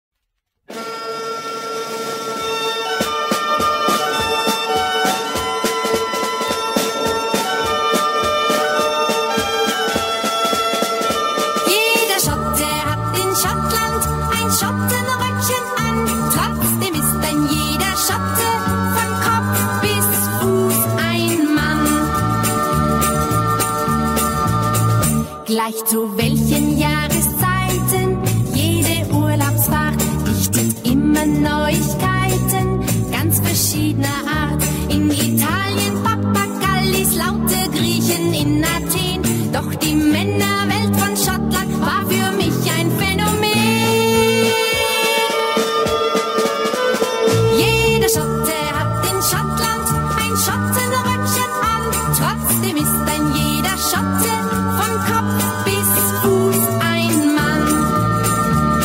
zangeresje